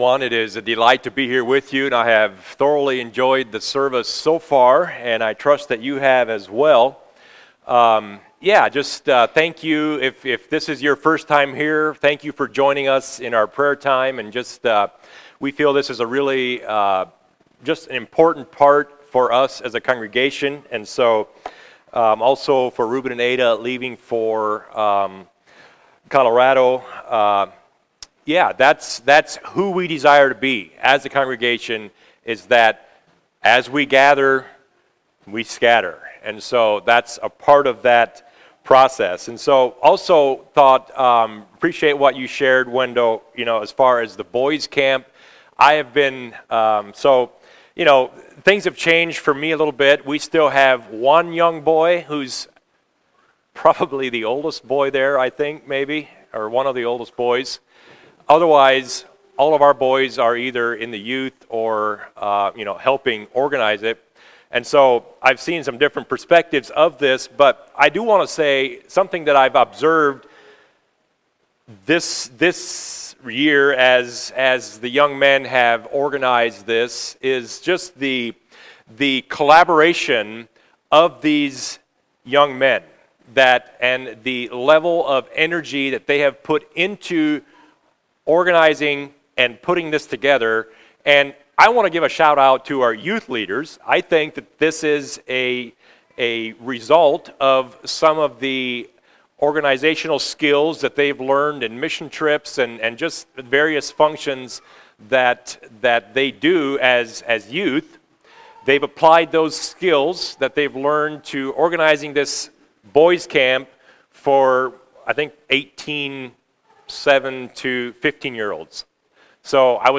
Home Sermons Healthy Church Are You a True Worshiper?